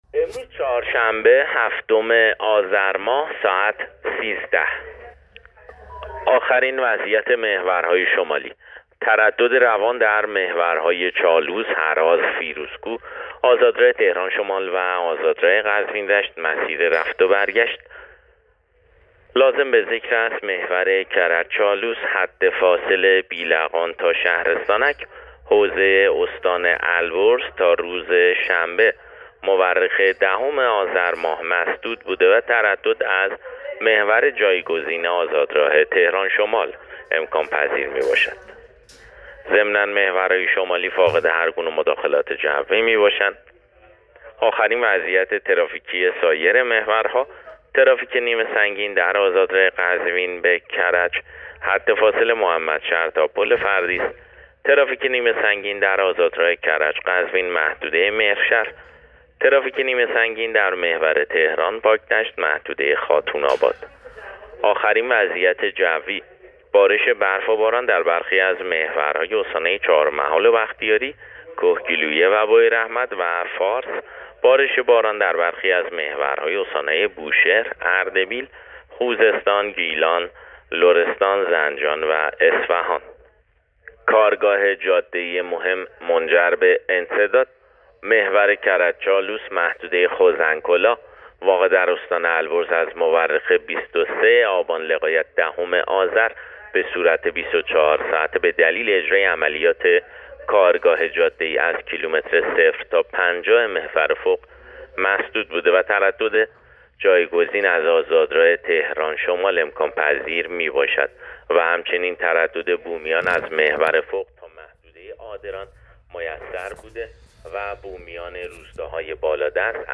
گزارش رادیو اینترنتی از آخرین وضعیت ترافیکی جاده‌ها تا ساعت ۱۳ هفتم آذر؛